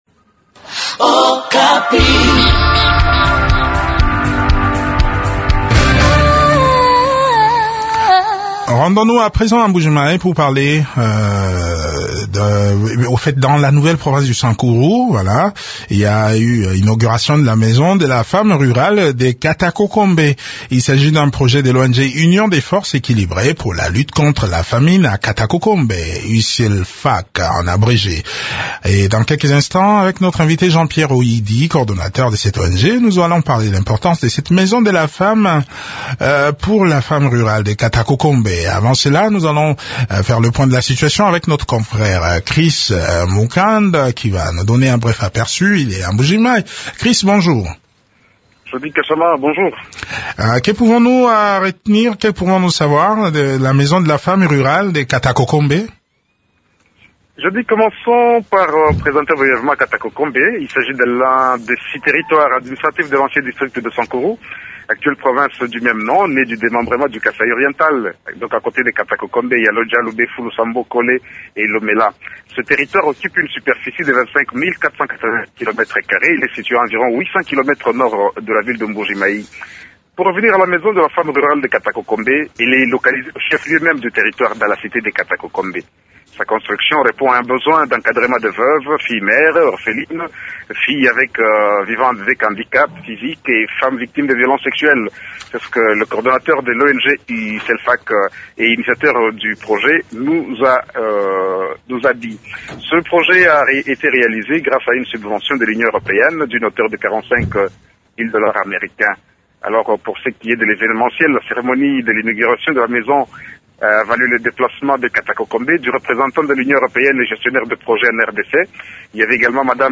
Le point sur le mode de fonctionnement de ce centre de formation dans cet entretien